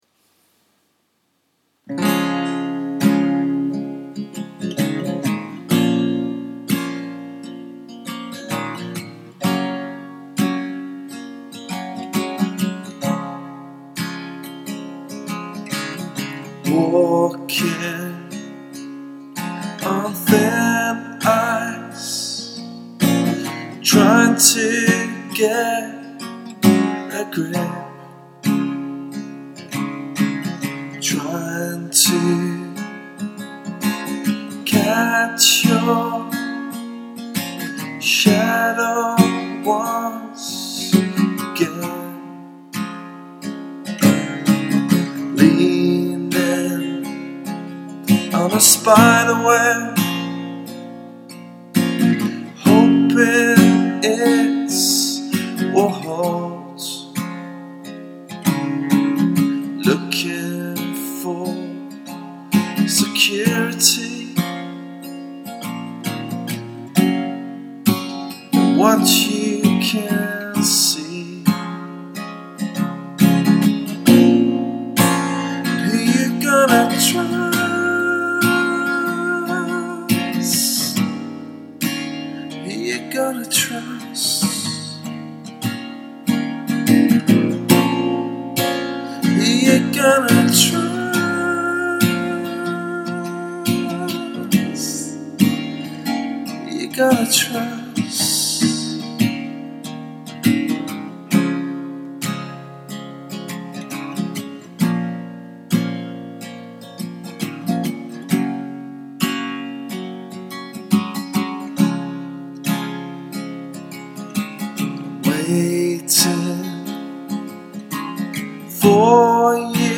Walking on thin ice - demo track